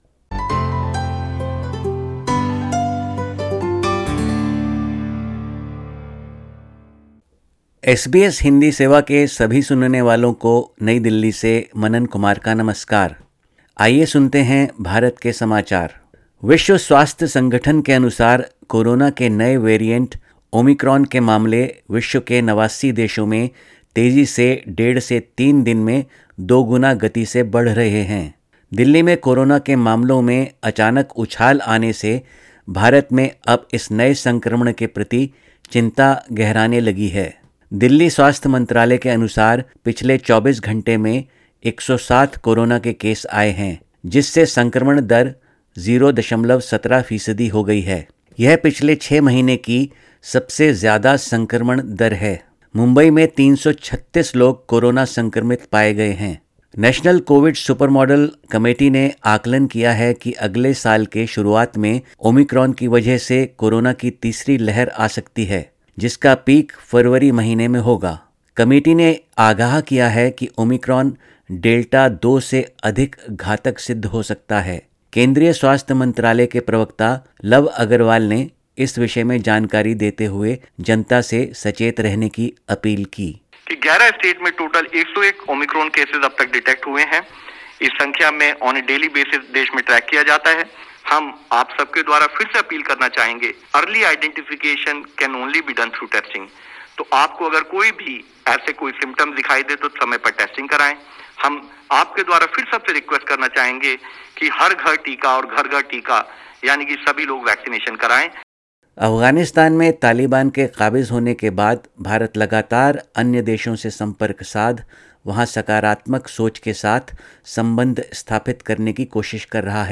20_dec_21_sbs_hindi_india_news_bulletin.mp3